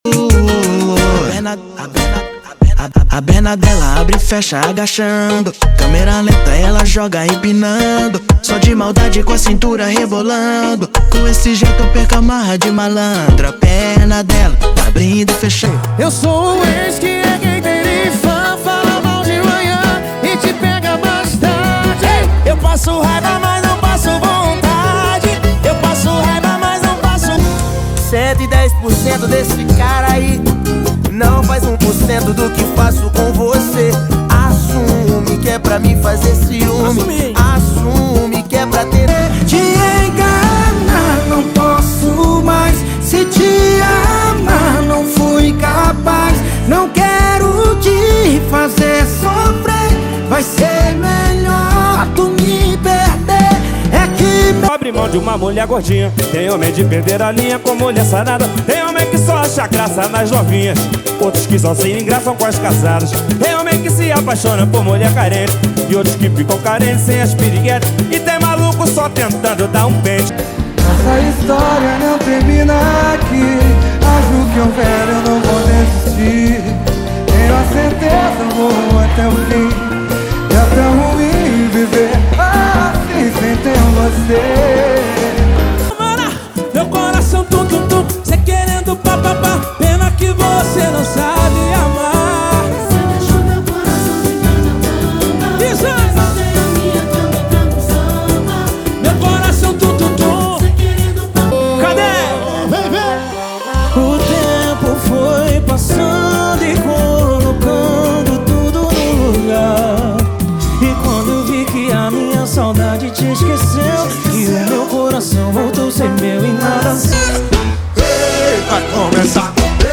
• Pagode e Samba = 50 Músicas
• Sem Vinhetas